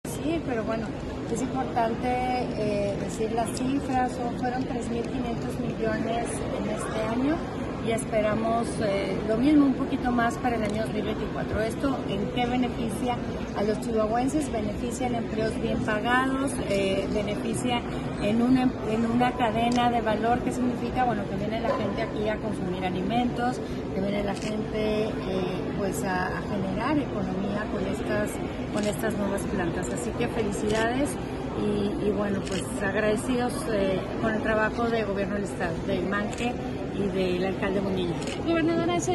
AUDIO: MARÍA EUGENIA CAMPOS, GOBERNADORA DEL ESTADO DE CHIHUAHUA